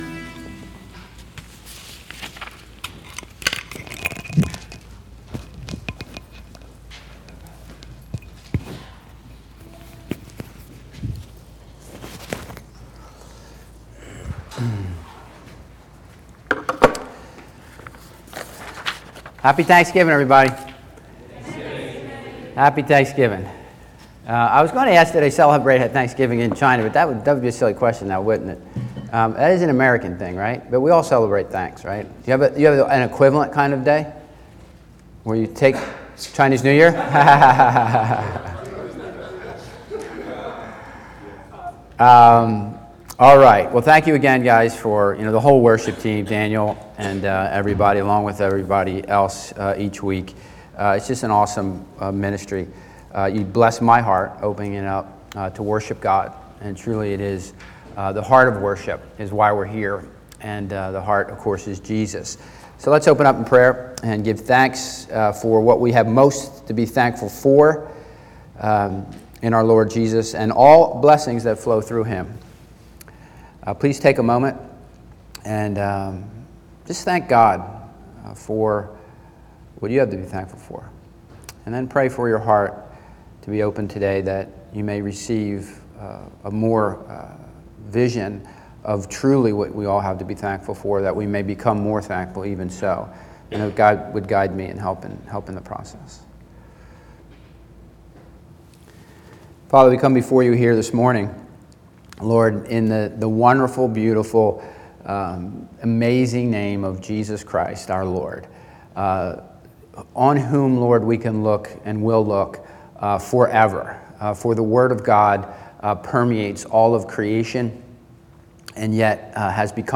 Bible Text: 1 Peter 1:3-7 | Preacher: